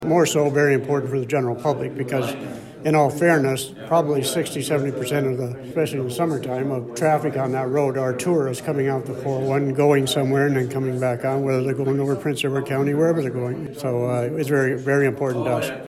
Tyendinaga Township Reeve and Hastings County Warden Rick Phillips speaks at a funding announcement on July 29, 2019.